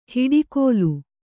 ♪ hiḍi kōlu